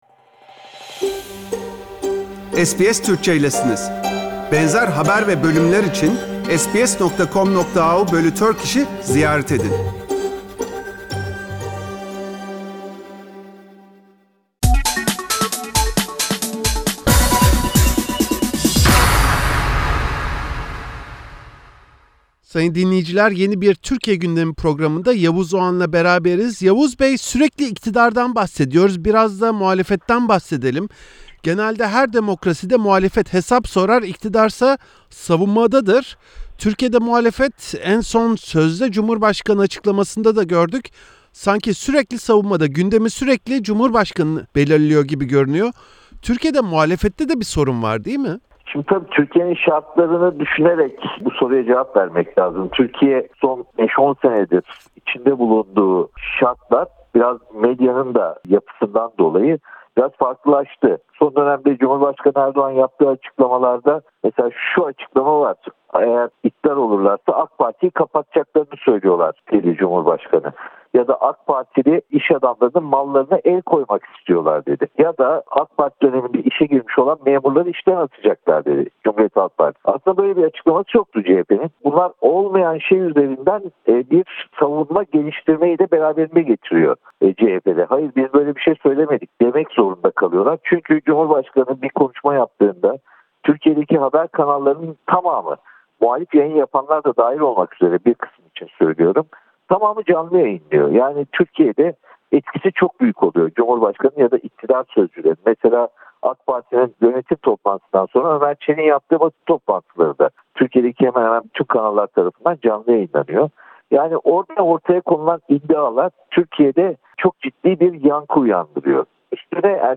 Türkiye’de bu hafta başlayan COVID-19 aşı kampanyası ile beraber, iktidar partilerinin yöneticilerinin aşı olması yeni bir tartışma yarattı. SBS Türkçe’ye konuşan gazeteci Yavuz Oğhan, aşının adil bir şekilde dağıtılacağı konusunda endişeler olduğunu söyledi.